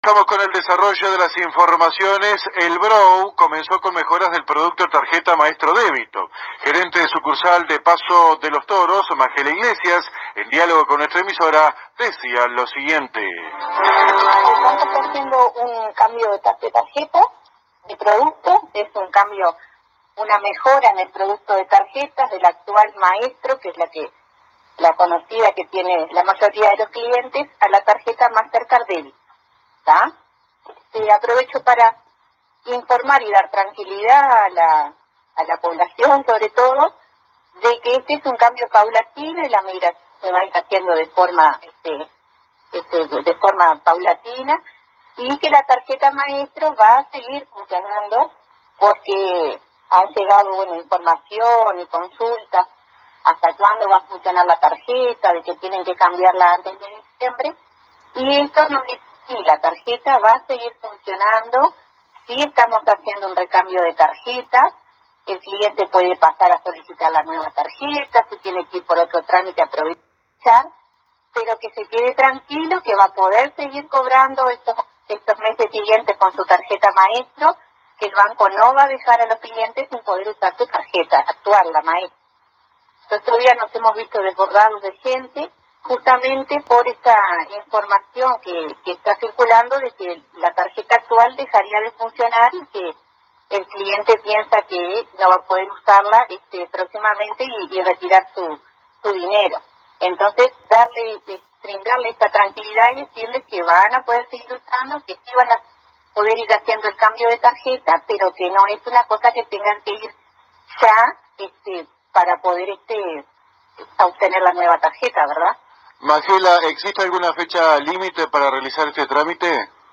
Fuente: AM 1110 Radio Paso de los Toros